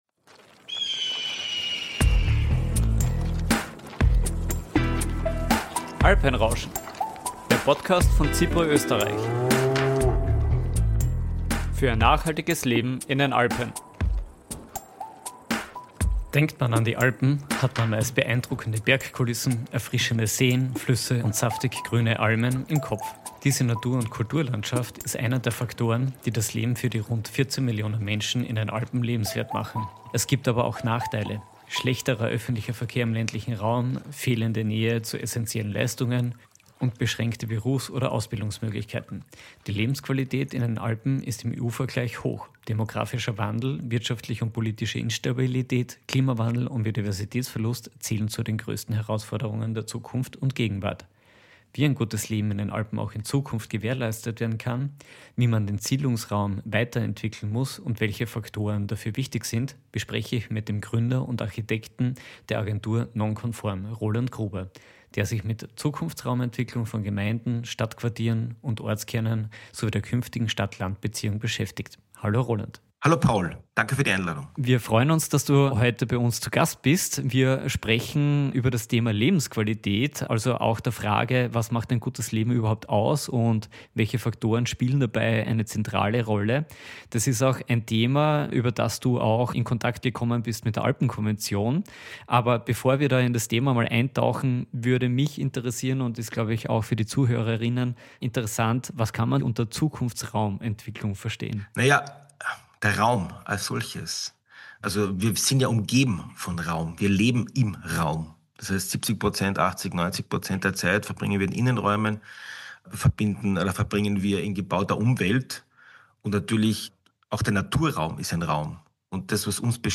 Gemeindeentwicklung und Lebensqualität kann nicht mit linearen Projekten erreicht werden, vielmehr sind es lebendige Prozesse, geprägt von Ver(w)irrungen und Ergebnisoffenheit - die in konkrete Umsetzungsprojekte übersetzt werden müssen. Ein Gespräch über Zukunftslust, Leerstandsentwicklung und die Rolle der positiven Psychologie.